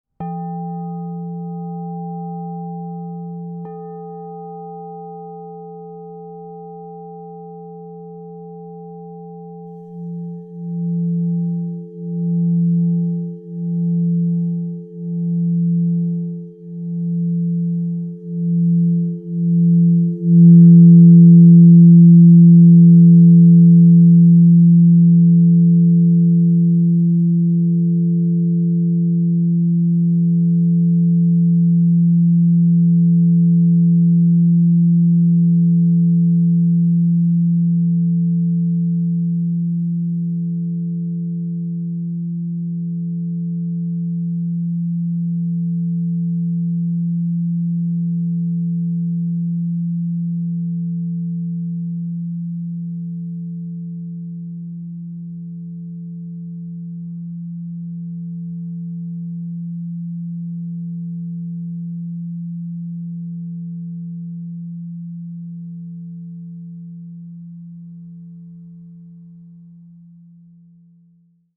Rose Quartz, Platinum 12" F -45 - Divine Sound